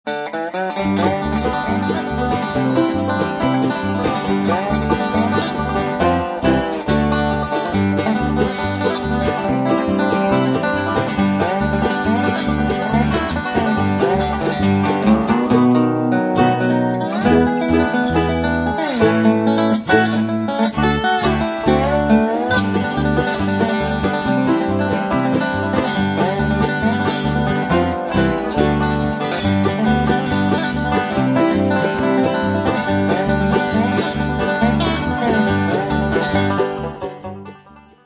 (instr.)
banjo
dobro
mandolin